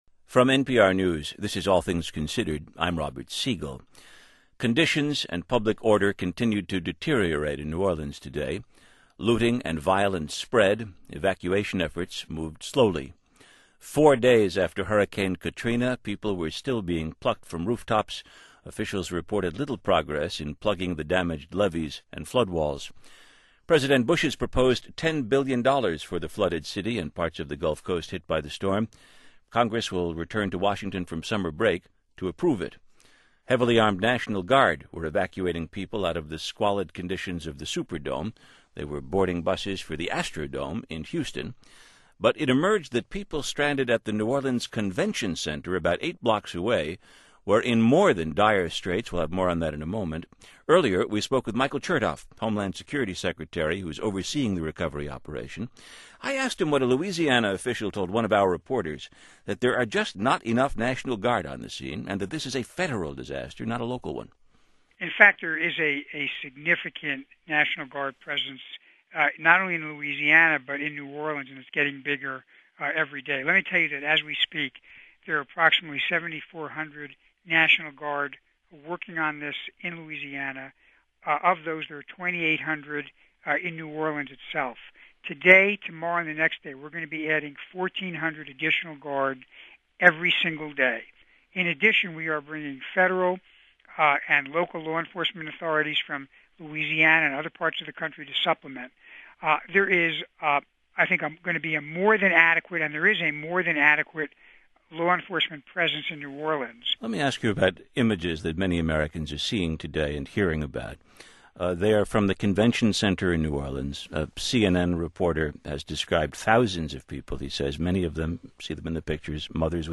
Four days after Hurricane Katrina, NPR's Robert Siegel spoke with Michael Chertoff, then the U.S. Secretary of Homeland Security. He was unaware of how bad things were at the convention center in New Orleans.